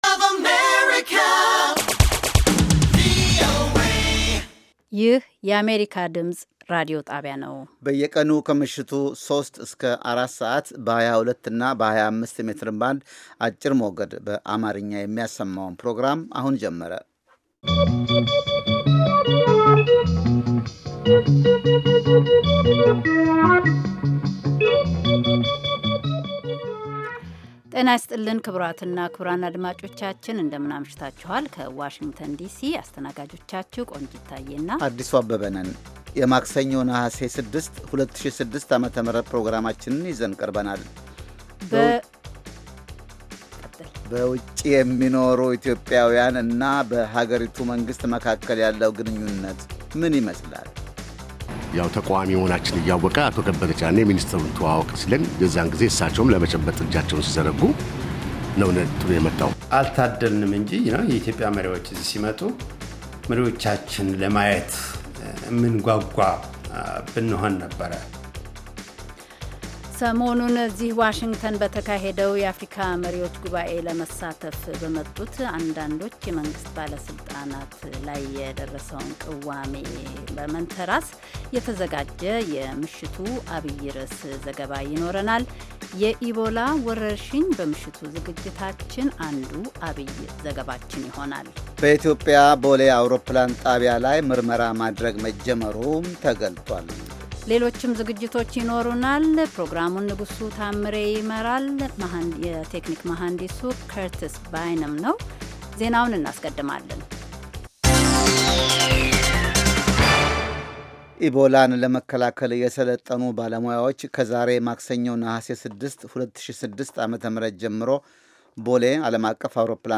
ከምሽቱ ሦስት ሰዓት የአማርኛ ዜና
ዘወትር ከምሽቱ ሦስት ሰዓት ላይ ኢትዮጵያና ኤርትራ ውስጥ ለሚገኙ አድማጮች በአማርኛ የሚተላለፉ ዜናዎች፣ ቃለመጠይቆችና ሌሎችም ትኩስ ዘገባዎች፤ እንዲሁም በባሕል፣ በጤና፣ በሴቶች፣ በቤተሰብና በወጣቶች፣ በፖለቲካ፣ በግብርና፣ በንግድ፣ በተፈጥሮ አካባቢ፣ በሣይንስ፣ በቴክኖሎጂ፣ በስፖርት፣ በሌሎችም አካባቢያዊና የመላ አፍሪካ ጉዳዮች ላይ ያተኮሩ መደበኛ ዝግጅቶች የተካተቱባቸው የአንድ ሰዓት ዕለታዊ ሥርጭቶች